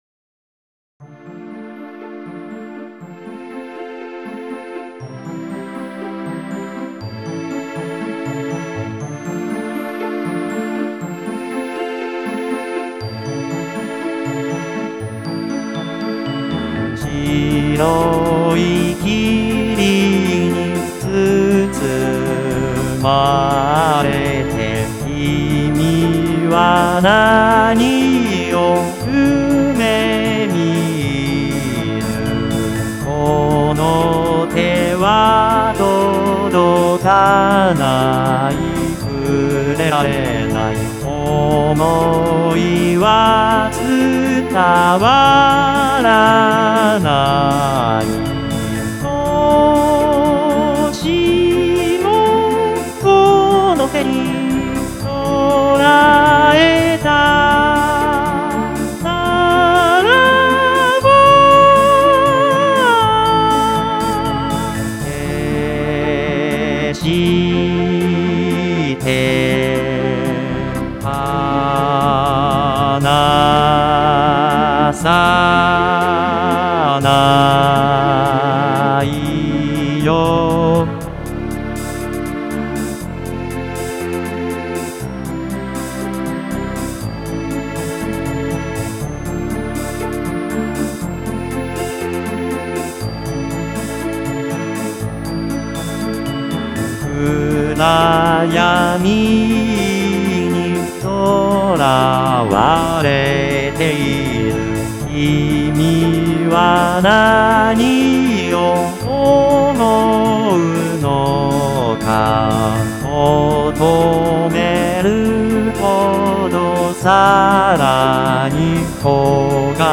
歌もの（ＶＯＣＡＬＯＩＤを使用したもの）